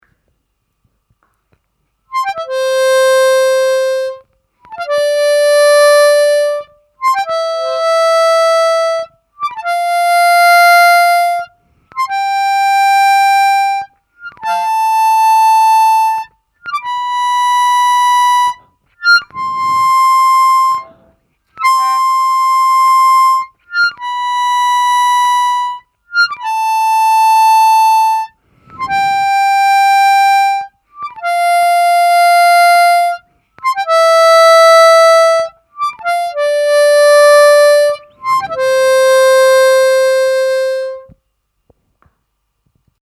Слайд (глиссандо) и дроп-офф на губной гармошке
Сыграть гамму «до мажор» +4-4+5-5+6-6-7+7 и обратно +7-7-6+6-5+5-4+4, к каждой ноте подъезжая техникой слайд (сверху).